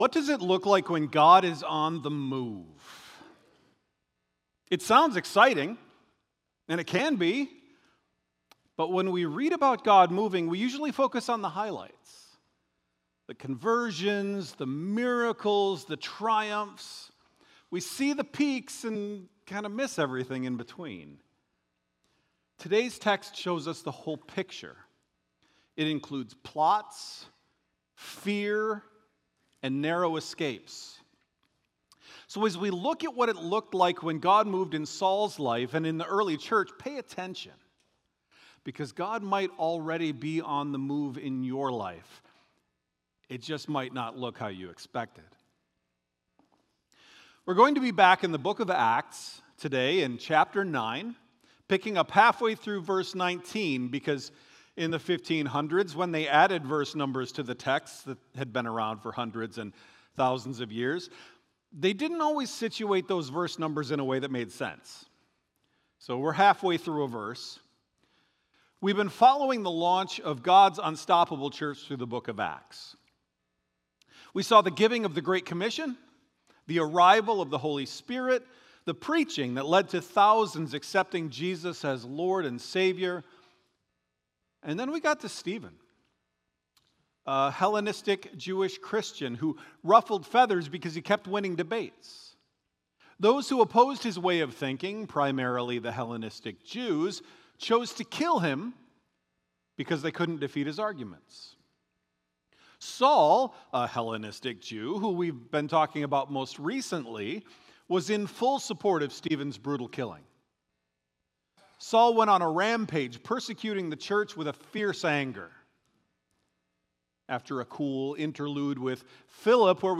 Sermon-Audio-October-5-2025.m4a